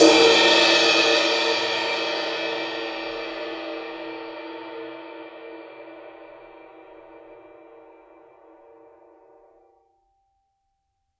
susCymb1-hitstick_fff_rr2.wav